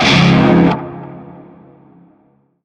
NarmerShawzinPhrygianChordB.ogg